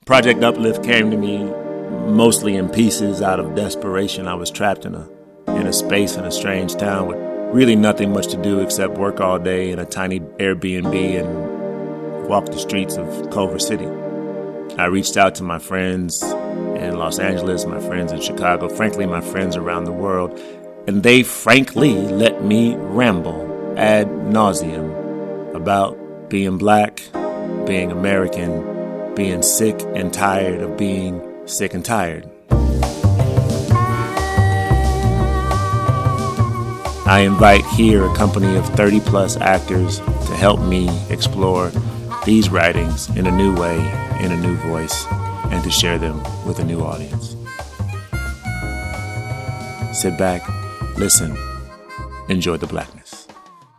A stellar company of voices bring to life a collection of moments that will forever define a generation.  A “two-week exercise” to “keep sane” has exploded into a year-long chronicle of our time.